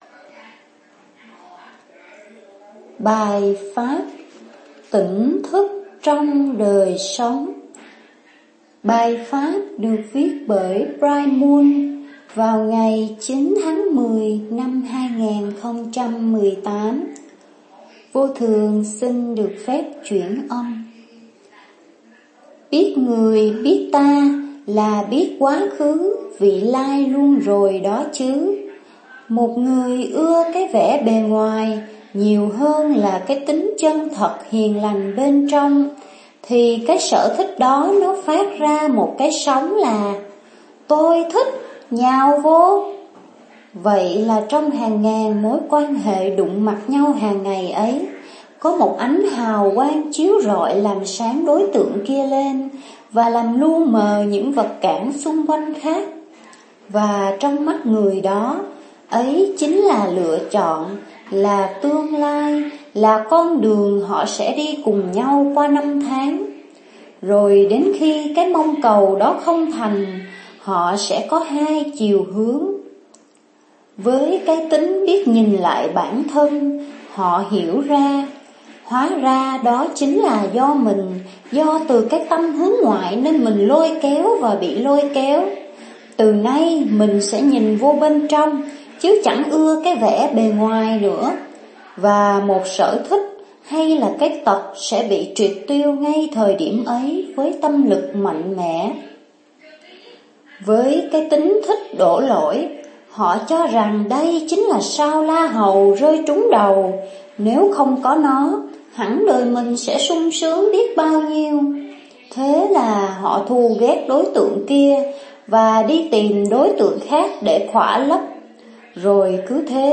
Tỉnh thức trong đời sống (chuyển âm)